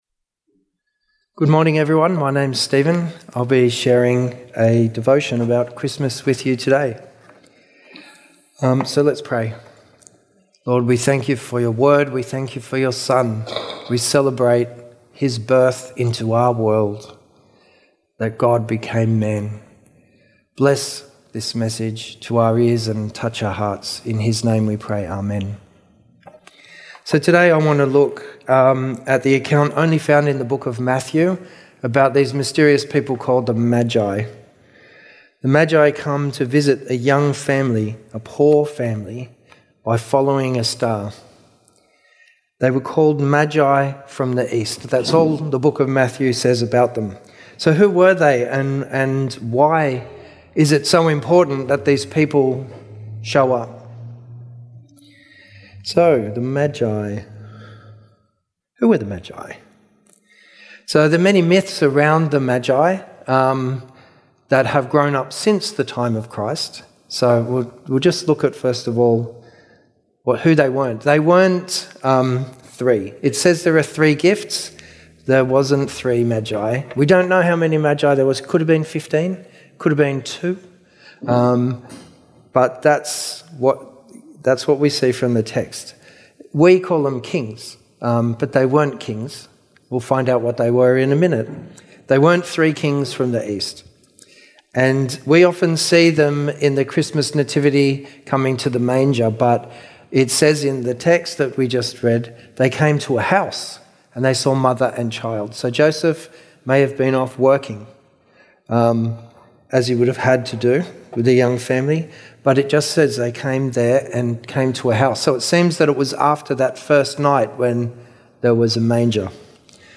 Summerhill Baptist Church Sermons